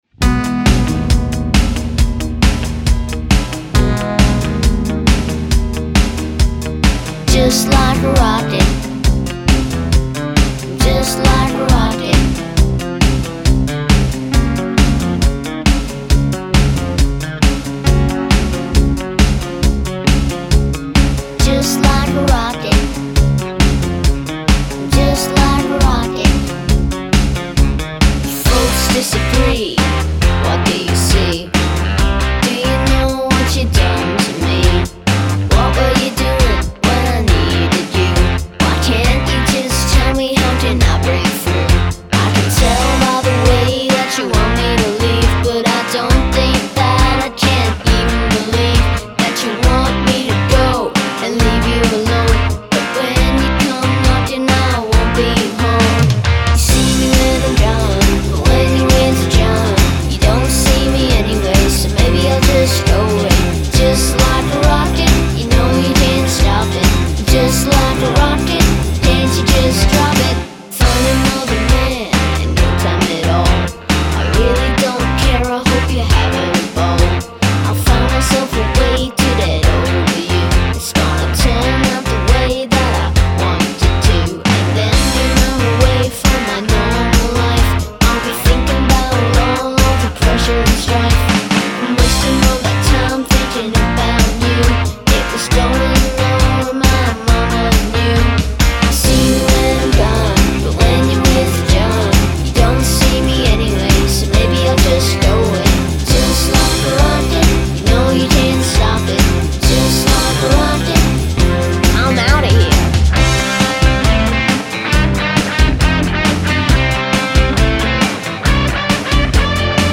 I really love the solo in this song!